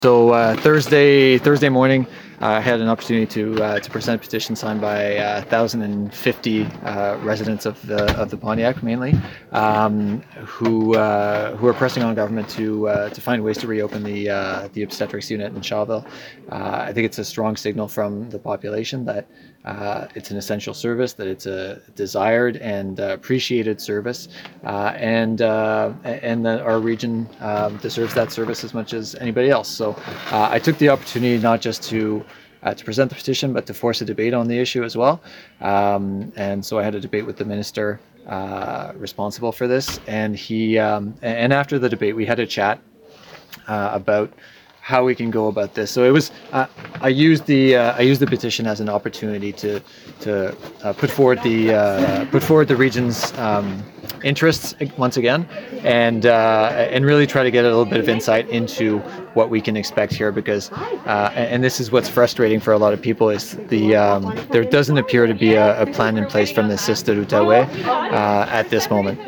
Pontiac MNA André Fortin talks about tabling a petition in the National Assembly asking for the return of obstetrics services to the Pontiac Hospital.